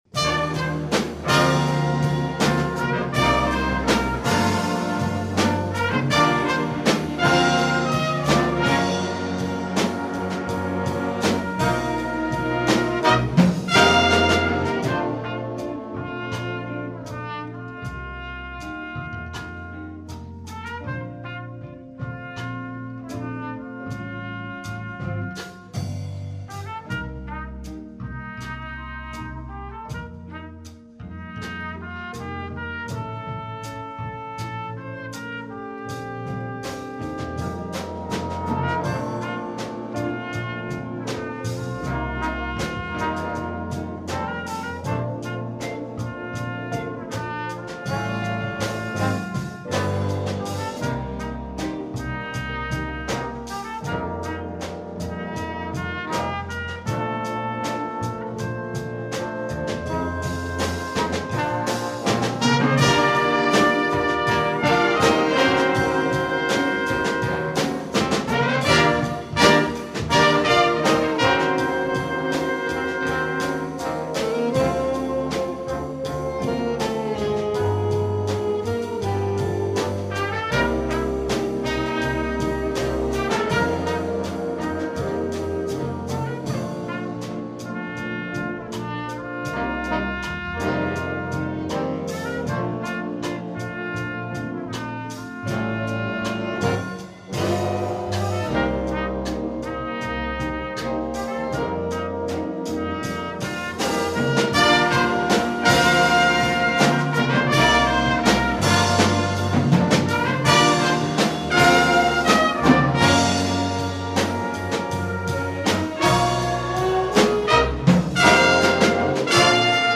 contemporary rock ballad featuring trumpet or flugelhorn
A written solo for trumpet is provided.